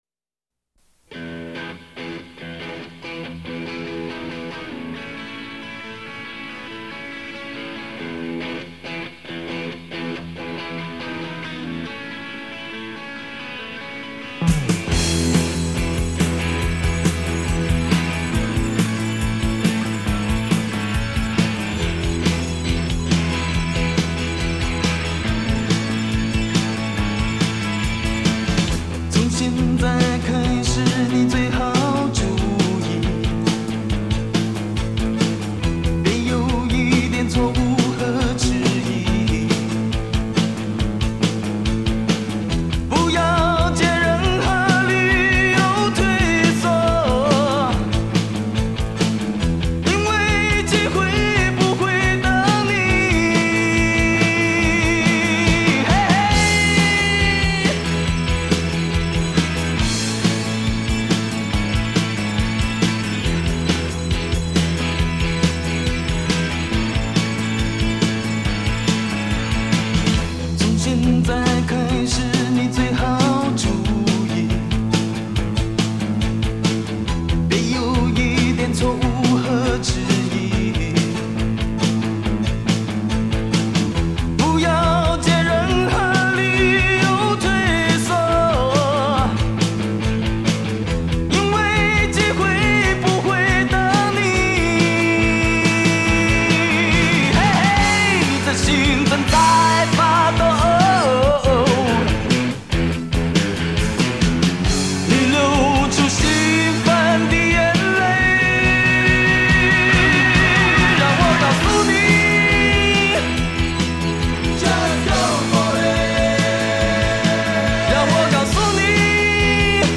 台湾摇滚乐团的记忆远比民歌运动来的零碎。